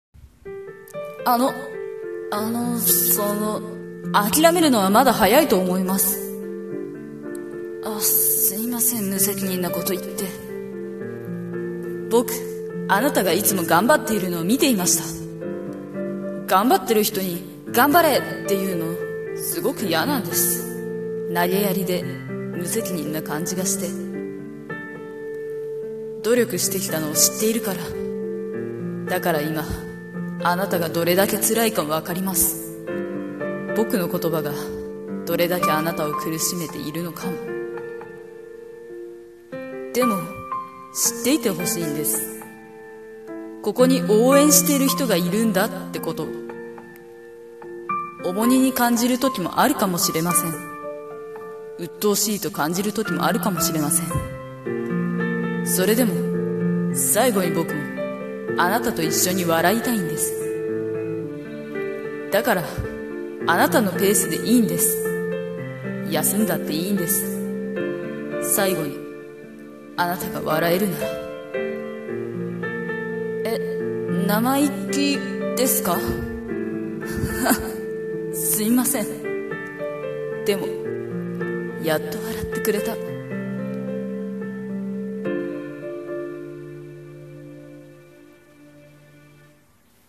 【朗読台本】応援する人【一人声劇】 / 朗読